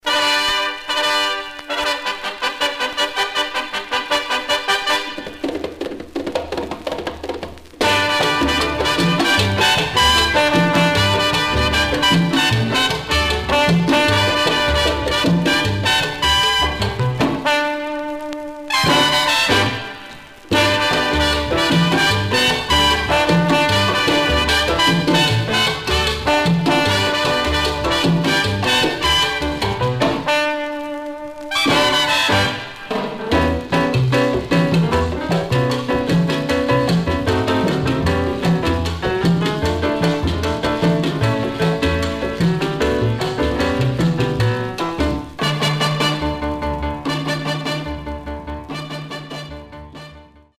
Surface noise/wear
Mono
Jazz (Also Contains Latin Jazz)